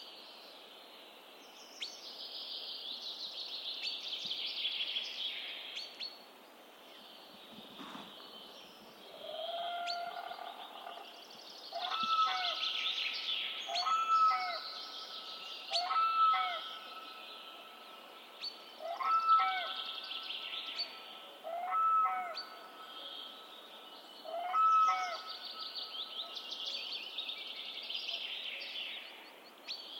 Grue cendrée - Mes zoazos
grue-cendree.mp3